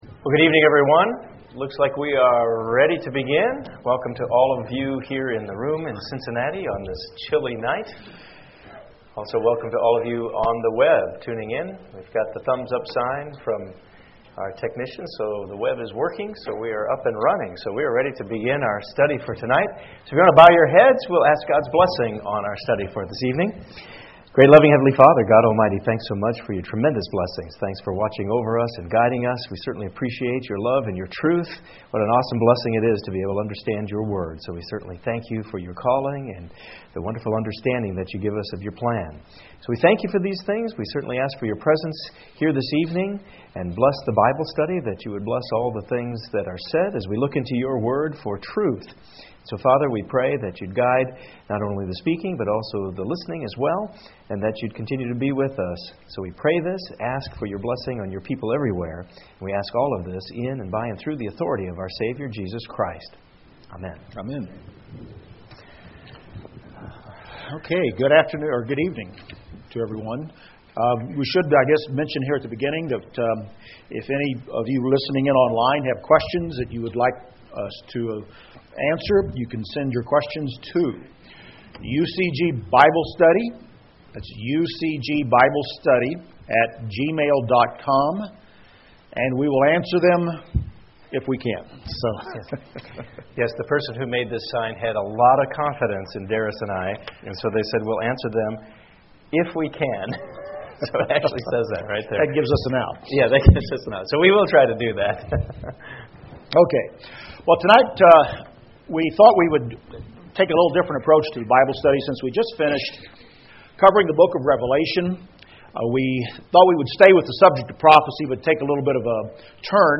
Bible Study: Exposing Conspiracy Theories-Illuminati, Harbinger & Others